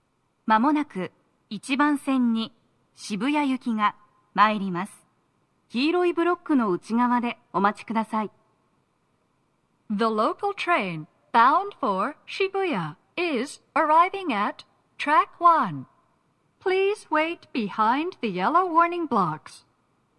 スピーカー種類 TOA天井型
🎵接近放送
鳴動は、やや遅めです。